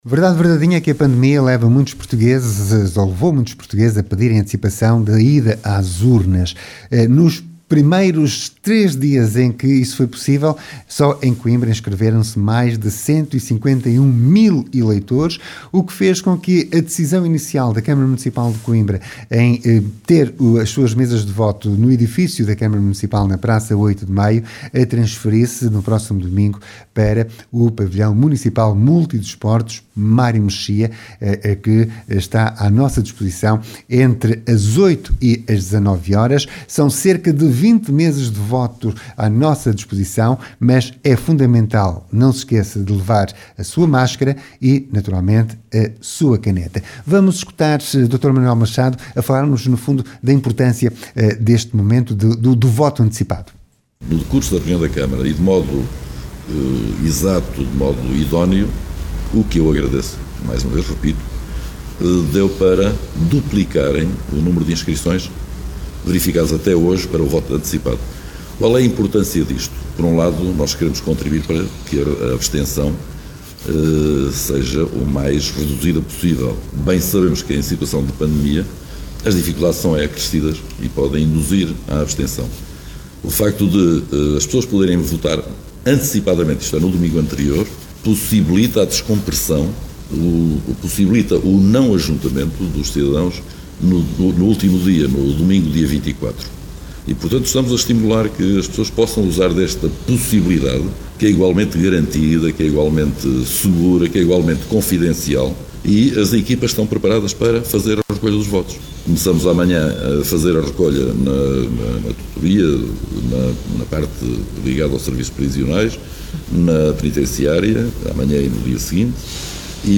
Câmara de Coimbra altera local de voto antecipado para o Pavilhão Mário Mexia pedindo que só deve aparecer quem se inscreveu e alerta para que não se esqueçam da máscara e da esferográfica. Manuel Machado, presidente da Câmara Municipal, explica a importância do voto antecipado e do esforço que o Município está a fazer de forma a que quem queira votar o possa fazer em segurança, incluindo presos e lares.